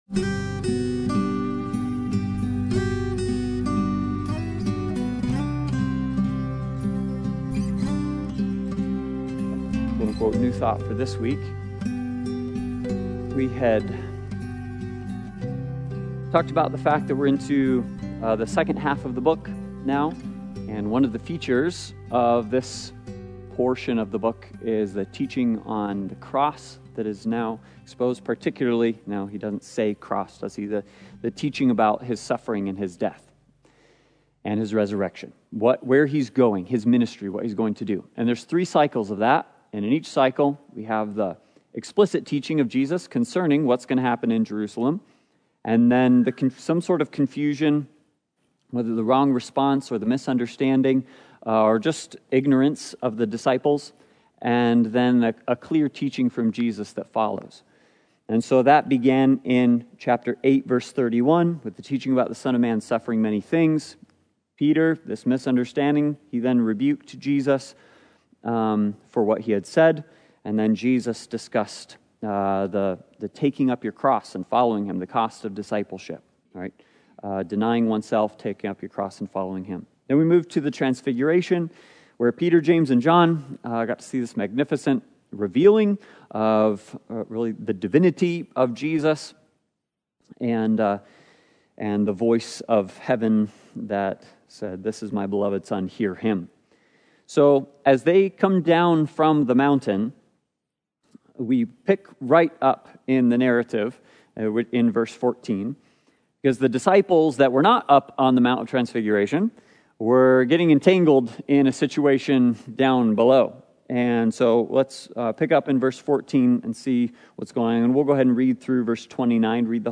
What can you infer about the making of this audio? The Gospel According to Mark Service Type: Sunday Bible Study « A Gospel-Centered Marriage